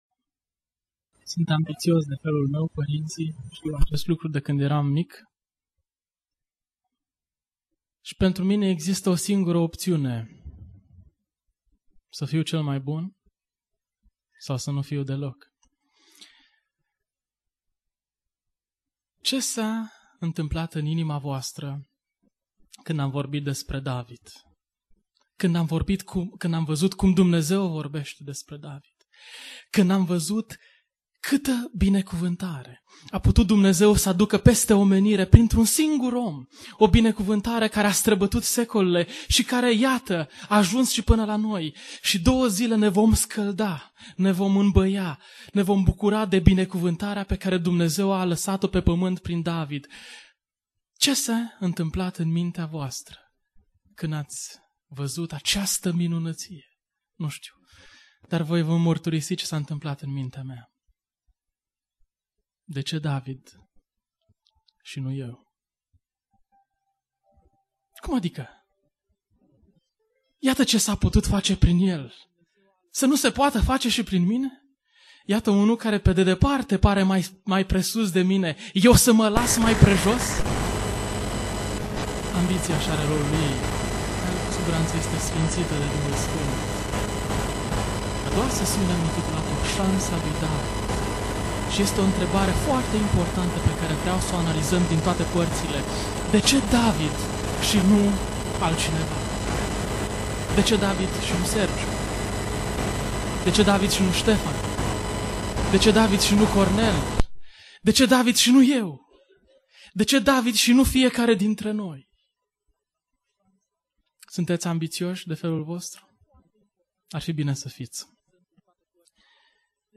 Conferinta David om dupa inima lui Dumnezeu-vineri-sesiunea1b